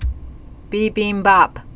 (bēbēmbäp)